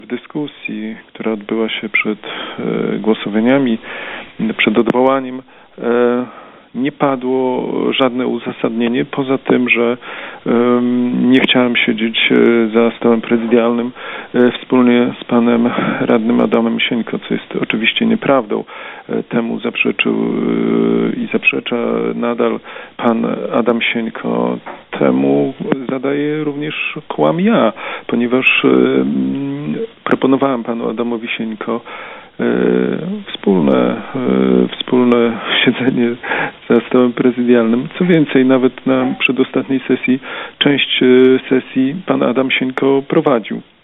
– Jest to nieprawdą – mówi Kleczkowski.